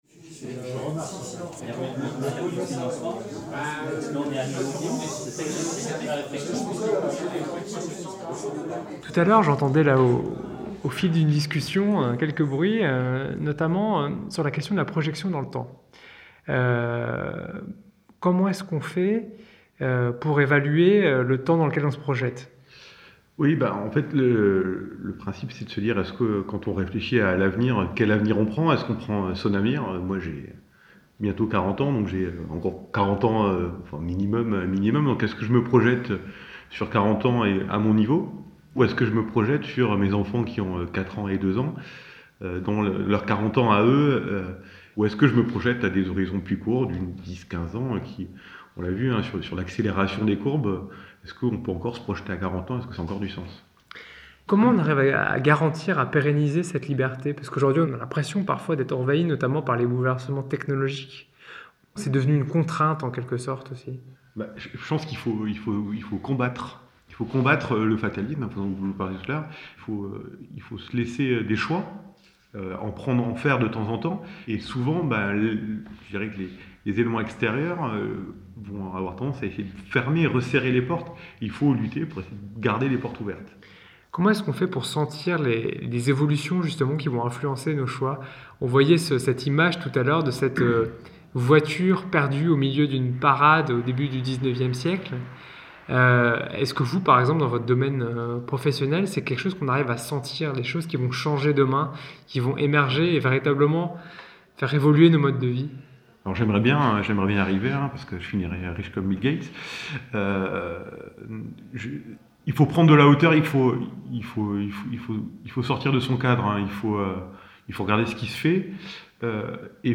Atelier de fin d'année de l’Institut Kervégan